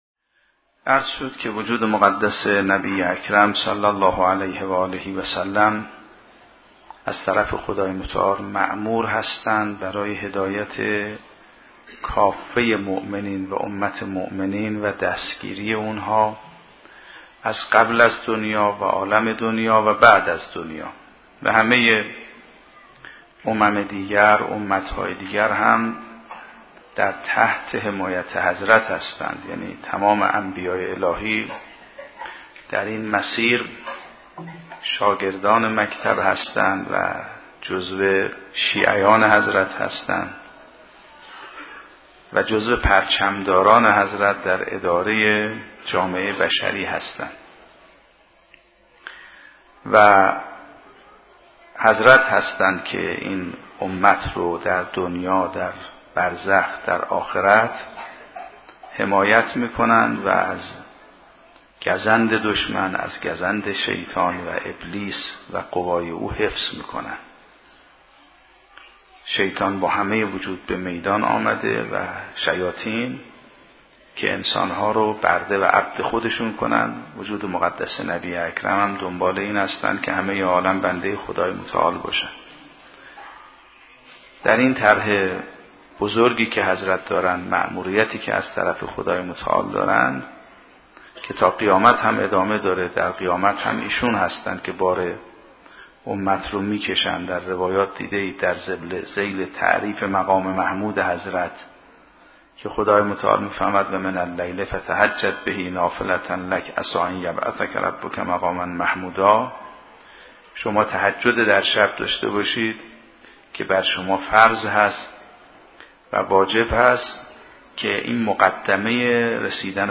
به گزارش سرویس اندیشه دینی پایگاه 598، آنچه پیش رو دارید دهمین جلسه سخنرانی آیت الله سید محمد مهدی میرباقری؛ رئیس فرهنگستان علوم اسلامی قم است که در دهه اول محرم الحرام سال 96 در هیأت ثارالله قم (مدرسه فیضیه) برگزار شده است.